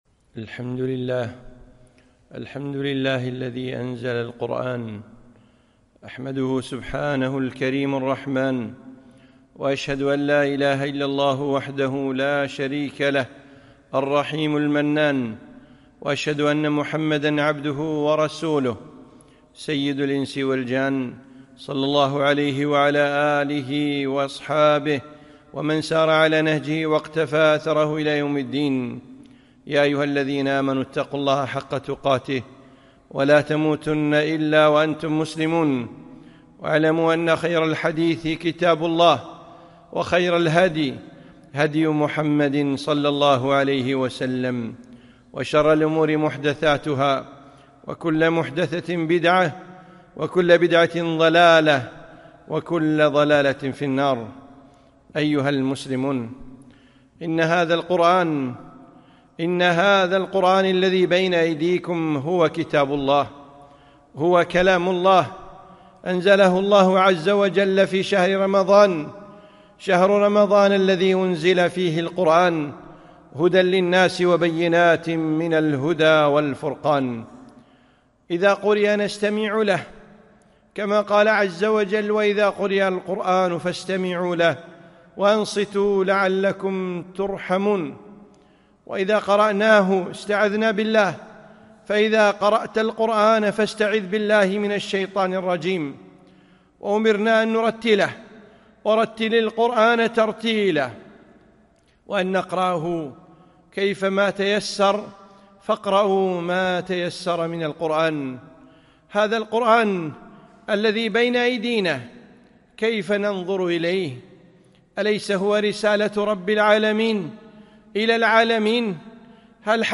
خطبة - هذا هو القرآن